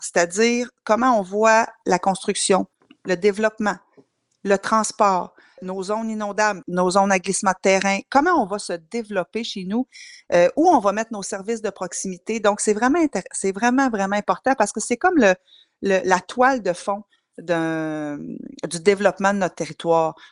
La préfète, Geneviève Dubois, a rappelé l’importance de bien le préparer.